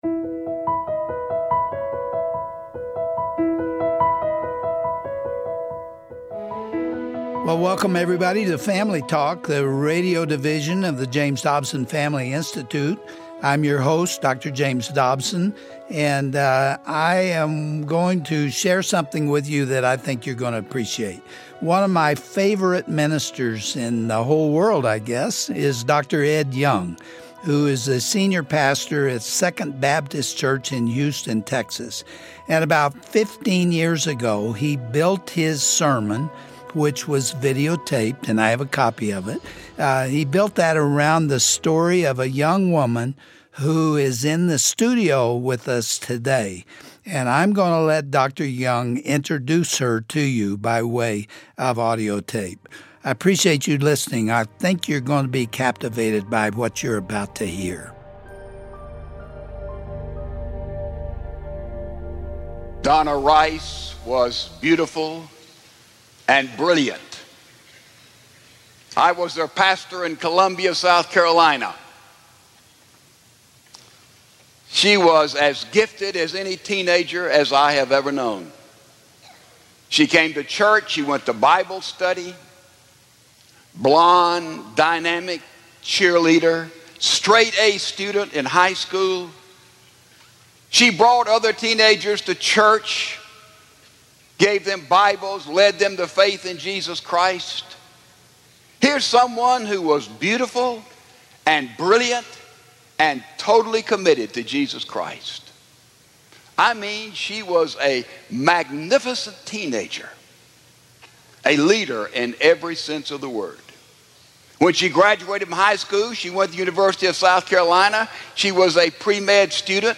On today’s edition of Family Talk, Dr. James Dobson interviews Donna Rice Hughes and discusses the early years of her life that culminated in betrayal and sexual exploitation. Donna proudly shares how God used those events to get her attention and reverse the trajectory of her life.